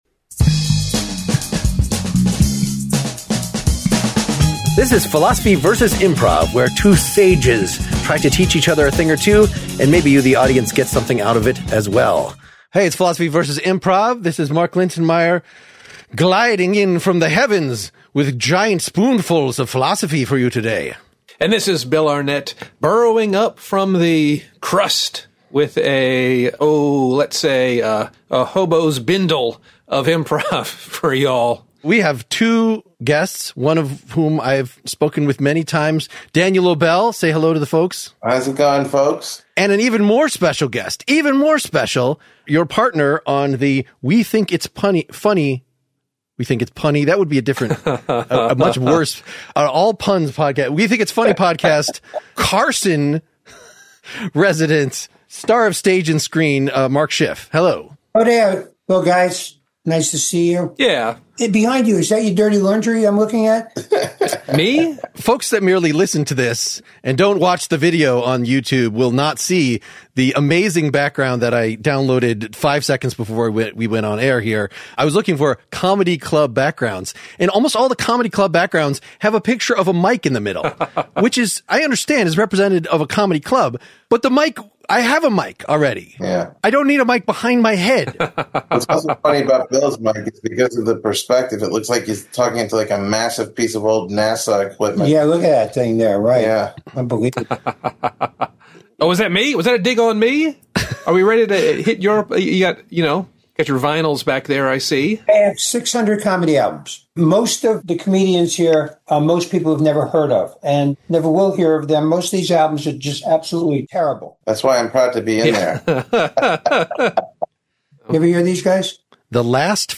We run a scene on hiring entertainment for the company party, and engage in some riffs that can only be called improv.